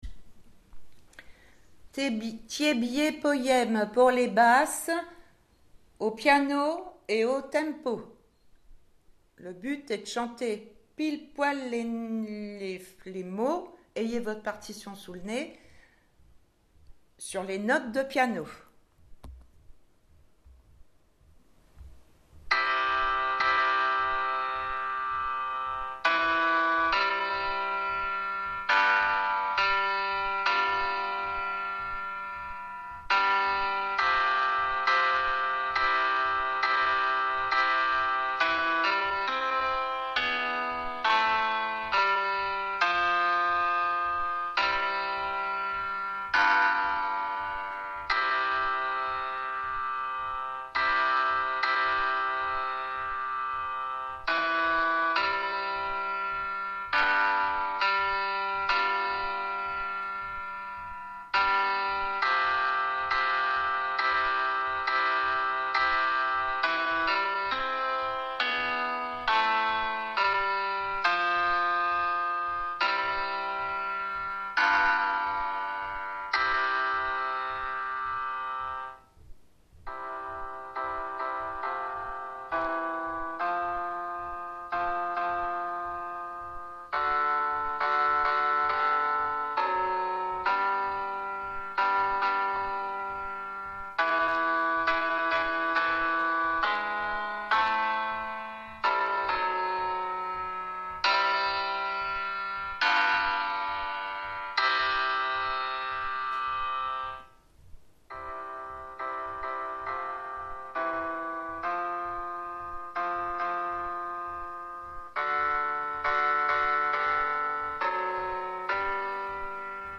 Tiébié piano Basse